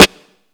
Snare (1).wav